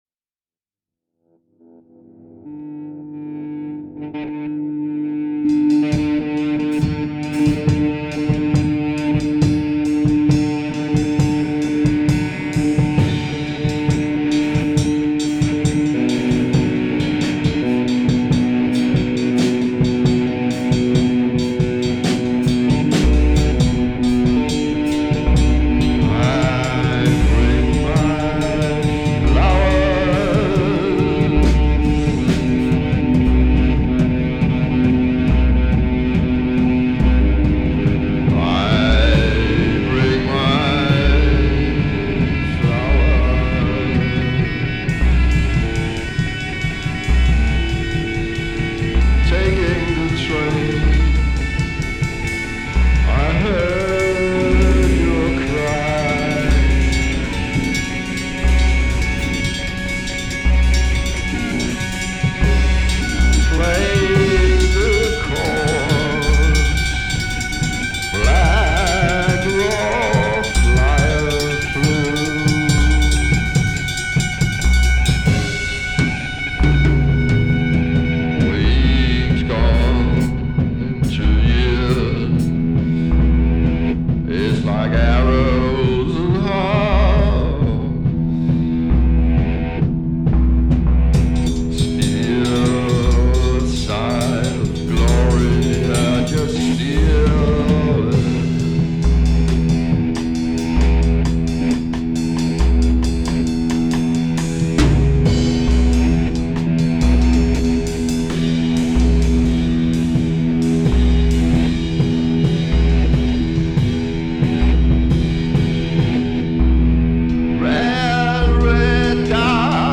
legendary danish drummer